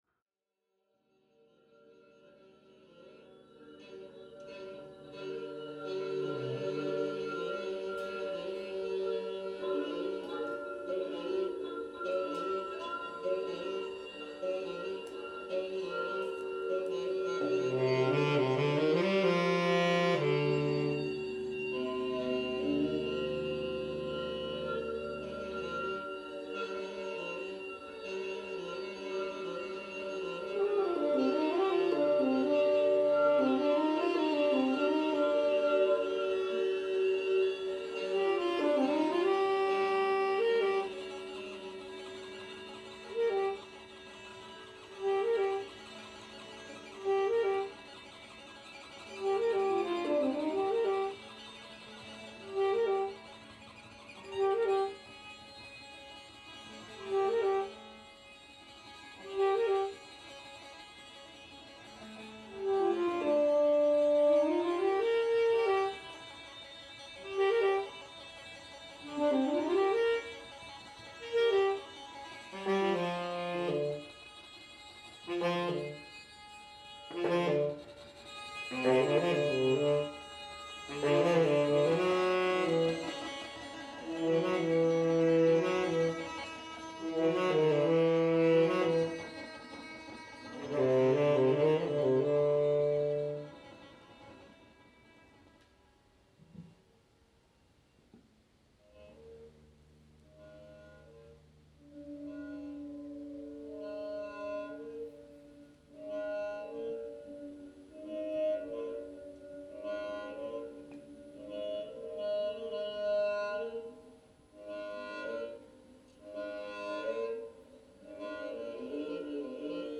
sax
guitar, water, contact microphone